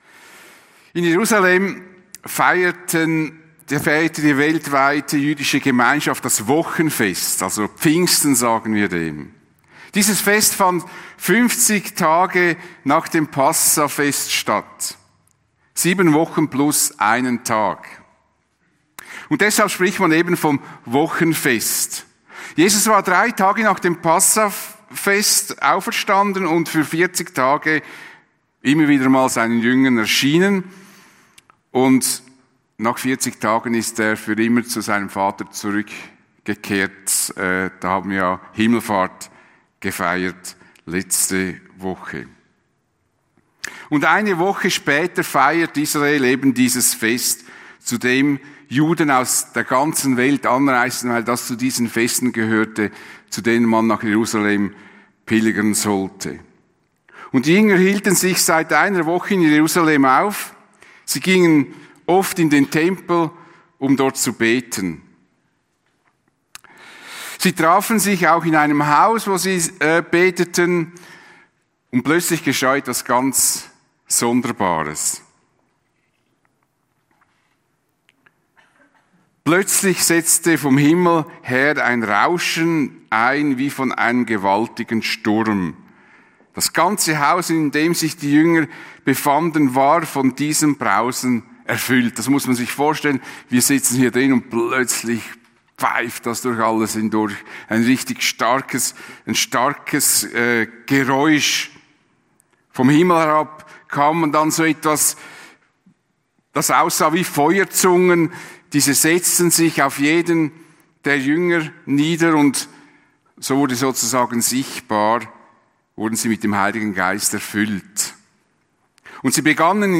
Prediger
Pfingsten